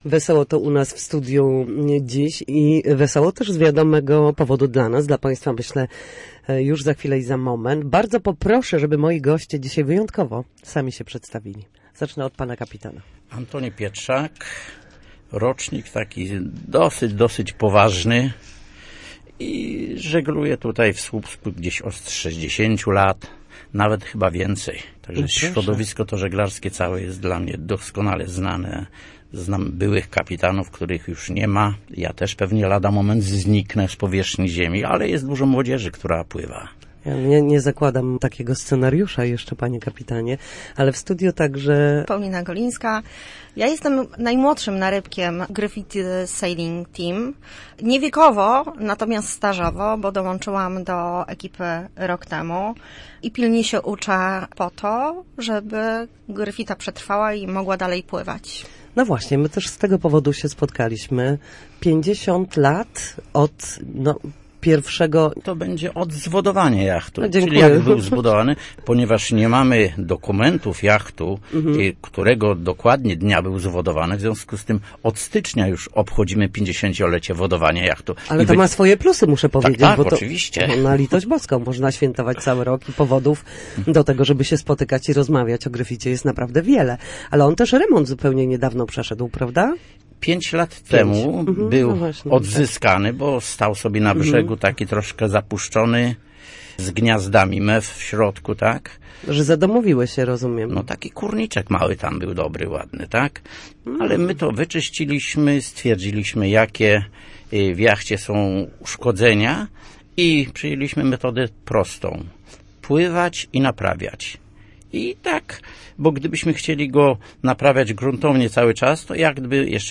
O jachcie z historią i o zaplanowanych atrakcjach opowiadali na antenie Radia Gdańsk